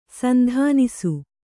♪ sandhānisu